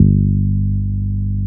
69 BASS GTR.wav